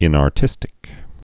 (ĭnär-tĭstĭk)